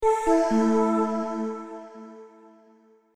alarm_timeout.wav